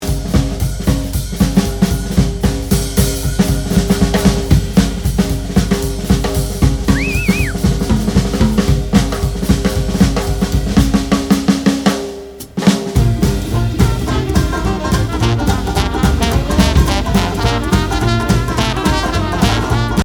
encore un autre exemple; même kit micro, mais autre batteur, autre Caisse Claire (plus résonnante); ici aussi pratiquement aucune correction à l'EQ pour coller au mieux au son naturel de la source (Jazz oblige!)
1113batterie3.mp3